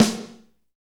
Index of /90_sSampleCDs/Northstar - Drumscapes Roland/DRM_Fast Shuffle/SNR_F_S Snares x